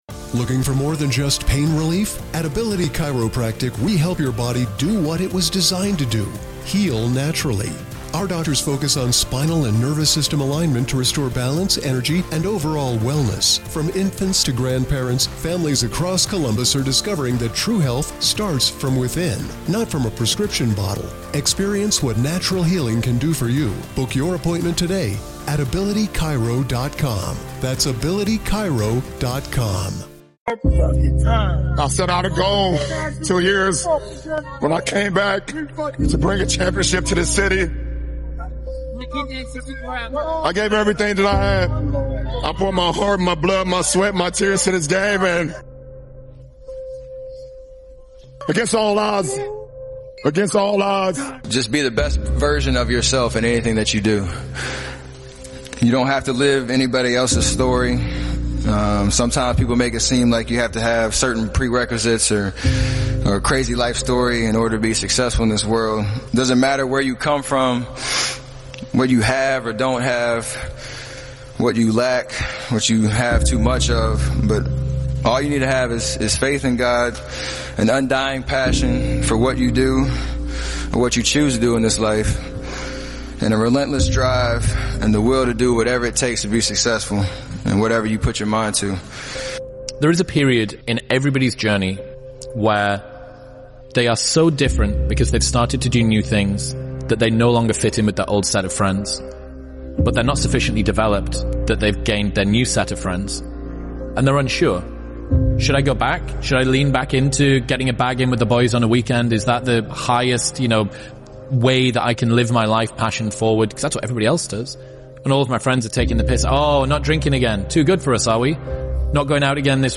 We bring you powerful stories and speeches from iconic figures like David Goggins, LeBron James, and Elon Musk. These speeches will ignite your inner fire, push you beyond your limits, and inspire you to conquer any challenge.